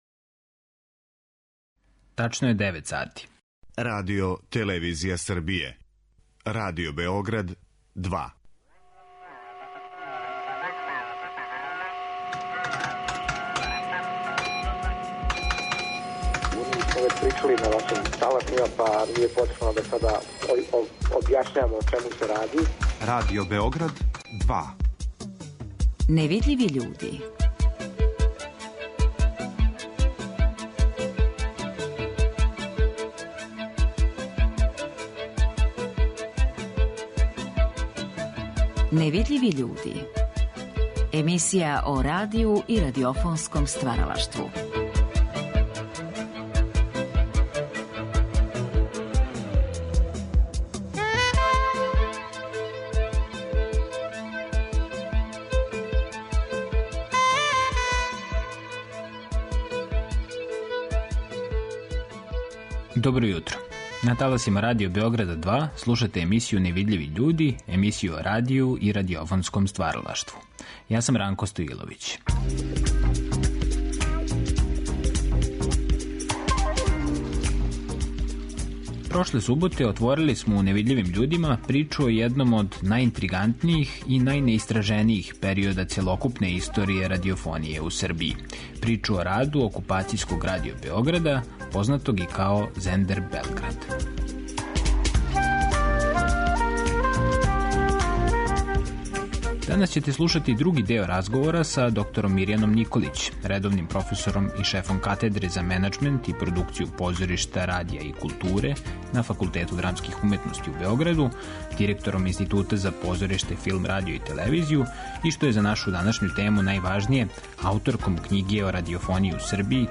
У наставку разговора биће речи о едукативним програмима Окупацијског Радио Београда, о продукцијски најзахтевнијим програмским форматима, као и о раду осталих радио-станица током Другог светског рата. Из ризнице нашег Тонског архива чућете накнадна сећања неких од директних актера ових догађаја.